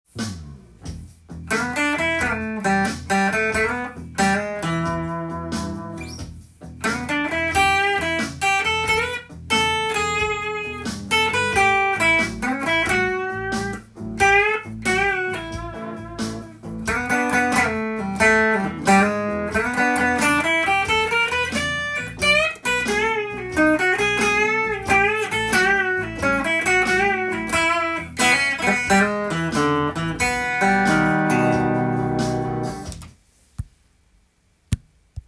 今日は、エレキギターで弾ける【E Bluesソロ】アドリブtab譜
カラオケにあわせて譜面通り弾いてみました
• Eマイナーペンタトニックスケール
• E7　A7　 B7　コードトーン
• ブルーノート
またコードに沿ったソロを弾くと渋い雰囲気を作れますので
最後の小節では、少しオシャレなコードBオーギュメント７